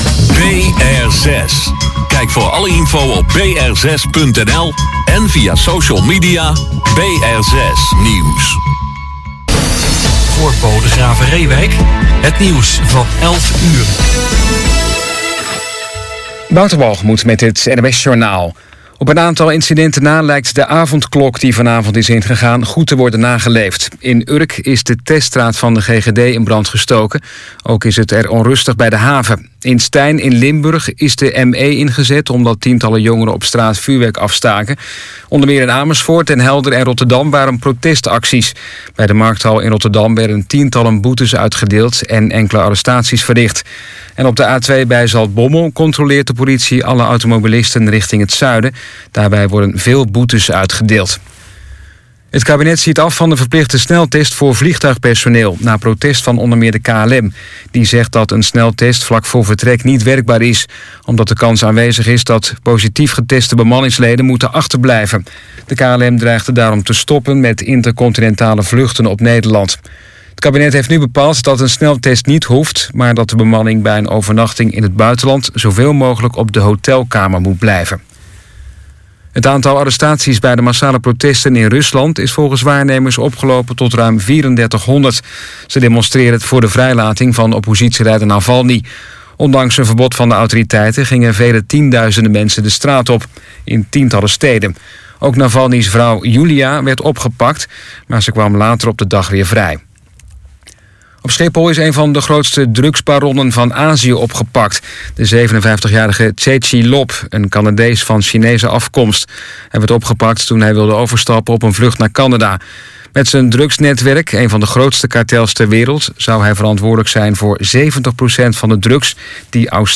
Piano
Tenor-sax
Van Gelder Studio, Hackensack, New Jersey